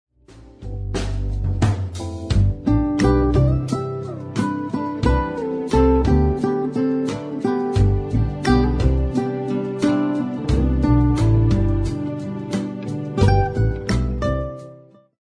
Instrumental Album of the Year